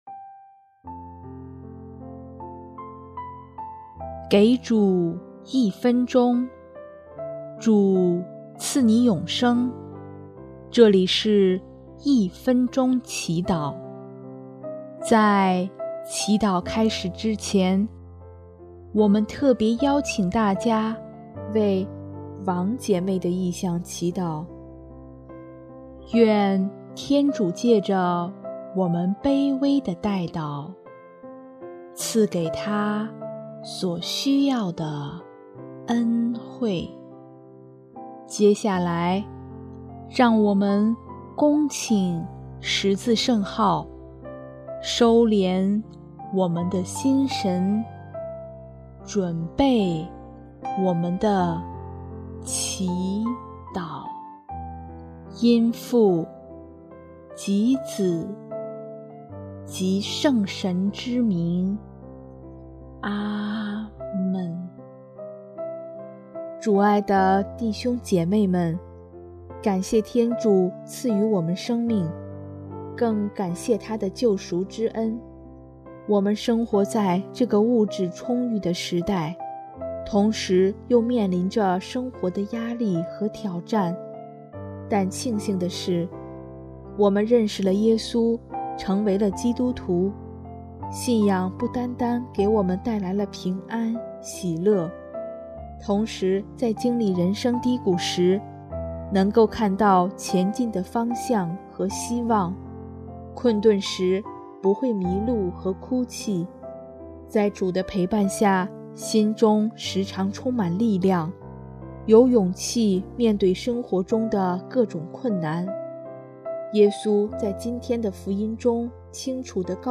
【一分钟祈祷】|5月8日 我们是有福的基督徒